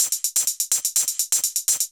UHH_ElectroHatC_125-02.wav